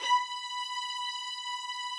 Updated string samples
strings_071.wav